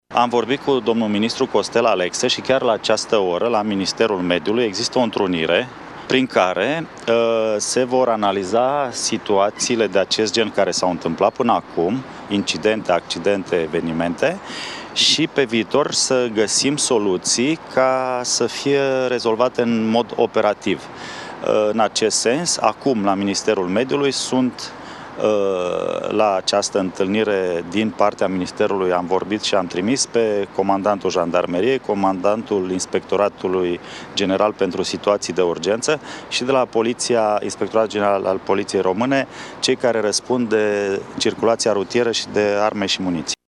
La întâlnire participă și reprezentanți ai Ministerului de Interne, a declarat cu puțin timp în urmă ministrul Marcel Vela: